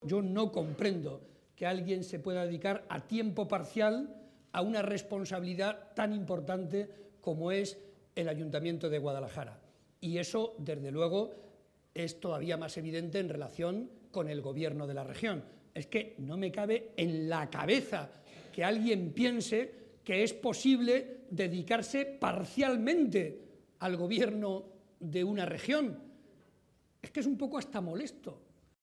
También ha valorado “el entusiasmo, la ilusión y las ideas tan claras” de Magdalena Valerio, a la que ha espetado “¡te veo como alcaldesa!” entre los aplausos de los asistentes, que abarrotaban el Salón de Actos del Conservatorio Provincial de Música.